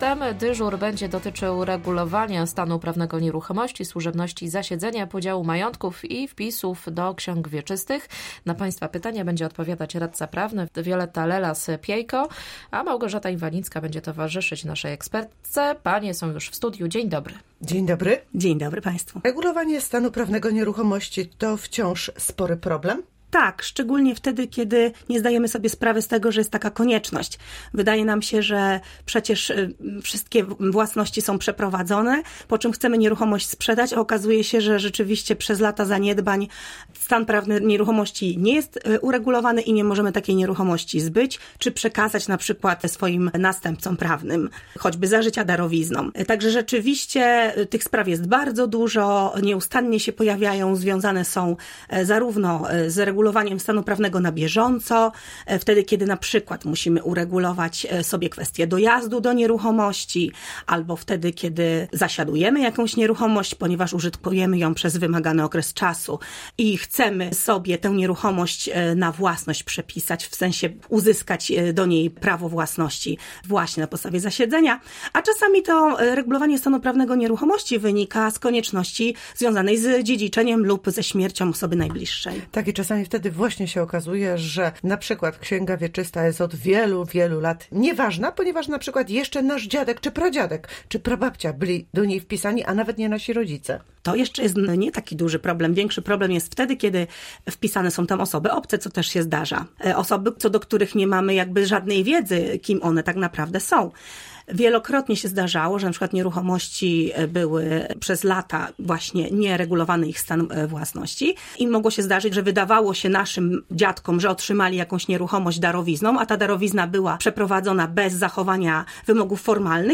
Co piątek w Kalejdoskopie zapraszamy ekspertów z rożnych dziedzin.